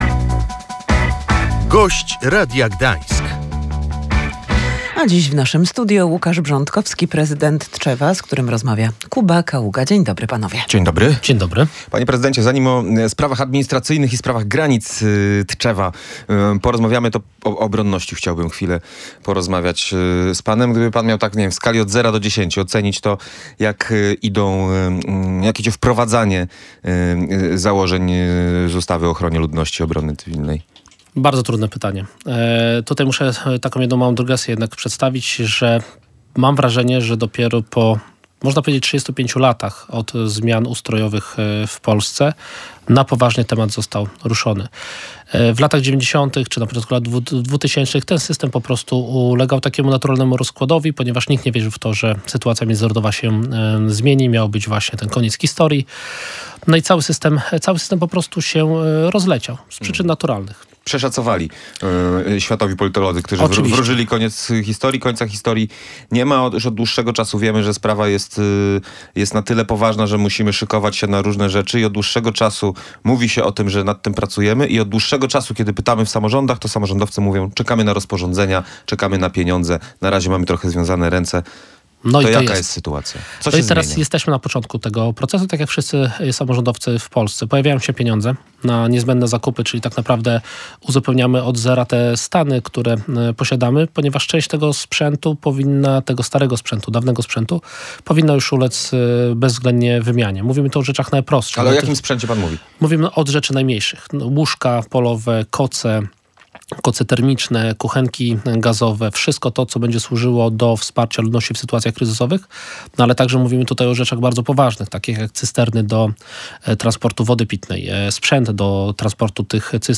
Oskarżenia wiceprezydent Tczewa o kradzież to pomówienia – powiedział w Radiu Gdańsk prezydent miasta Łukasz Brządkowski.
Przeczytaj artykuł: Prezydent Tczewa reaguje na doniesienia dotyczące swojej zastępczyni Łukasz Brządkowski mówił na naszej antenie, że ufa swojej zastępczyni.